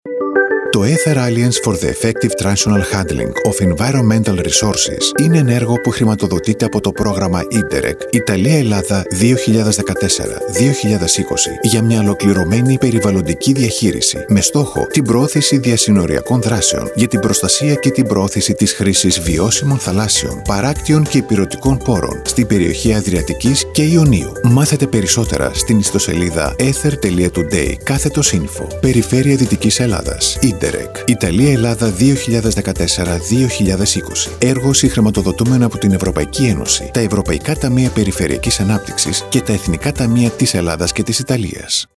Comercial, Natural, Seguro, Cálida, Empresarial
Explicador